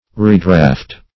Redraft \Re*draft"\ (r[=e]*dr[.a]ft"), v. t.